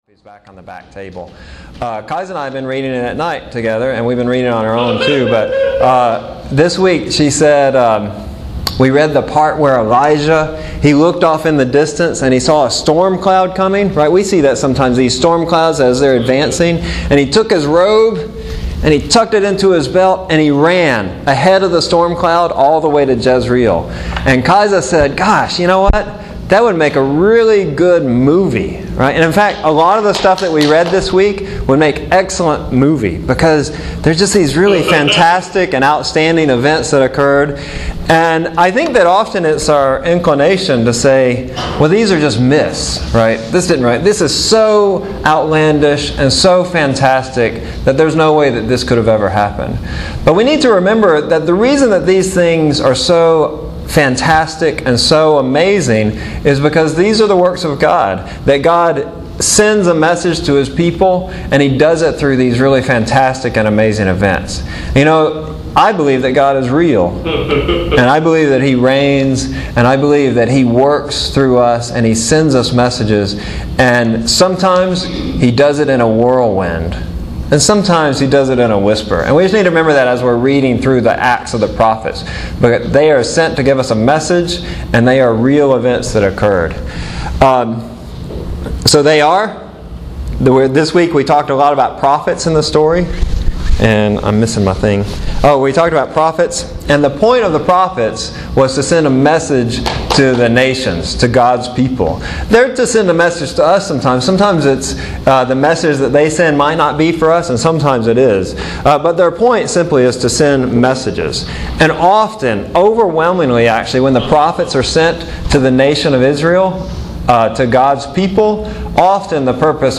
Again this series is part of a Church wide curriculum package so you may have actually heard similar sermons somewhere.